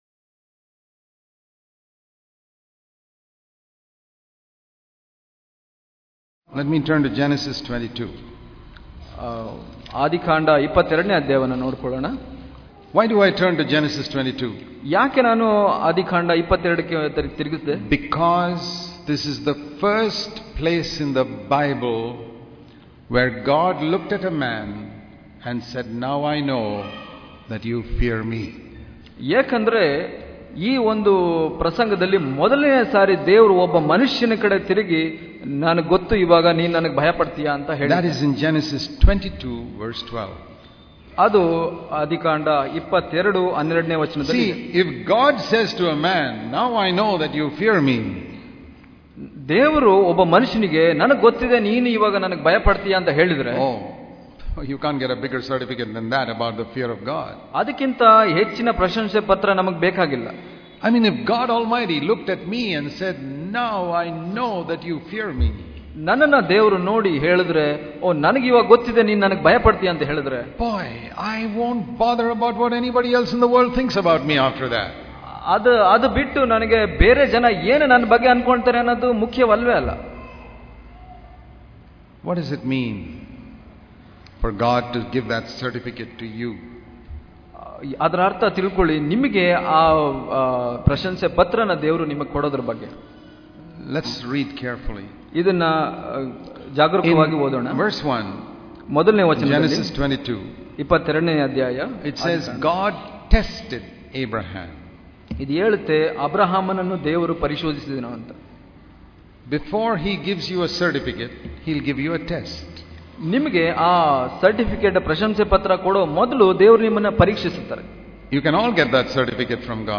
January 3 | Kannada Daily Devotion | God Tests us to See If we Fear God Daily Devotions